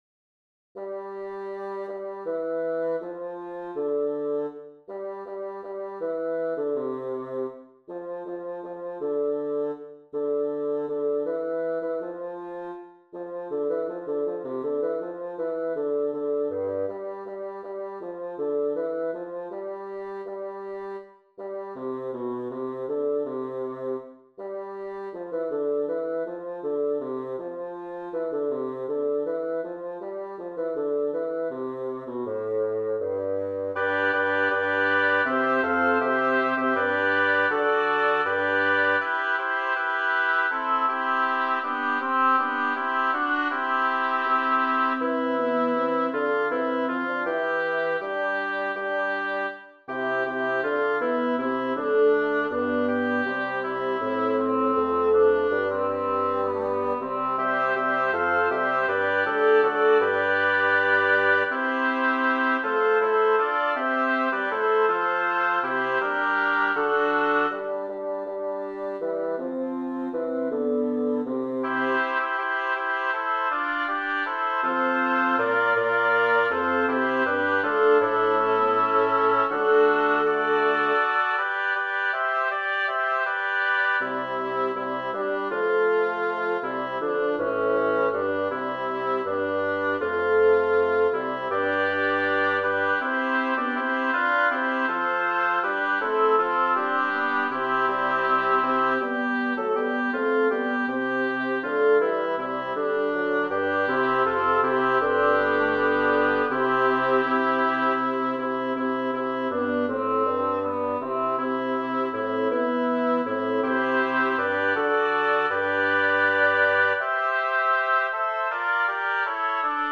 Title: Exiens homo Composer: Melchior Vulpius Lyricist: Number of voices: 6vv Voicing: SSATTB Genre: Sacred, Motet
Language: Latin Instruments: A cappella